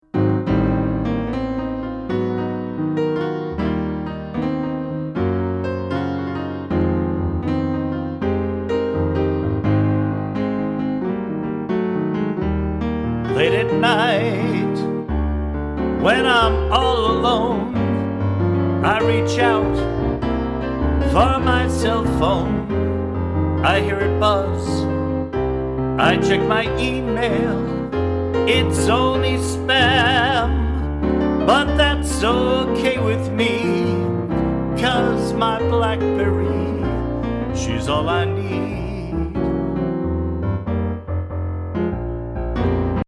musical parodies